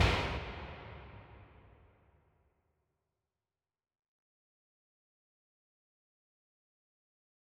MDMV3 - Hit 3.wav